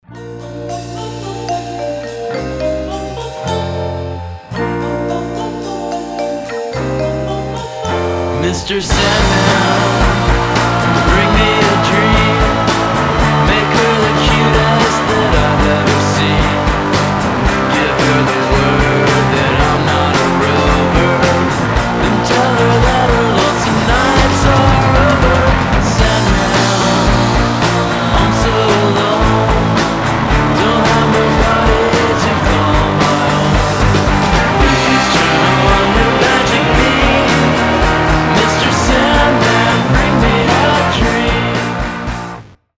• Качество: 320, Stereo
Cover
электрогитара
indie rock
rockabilly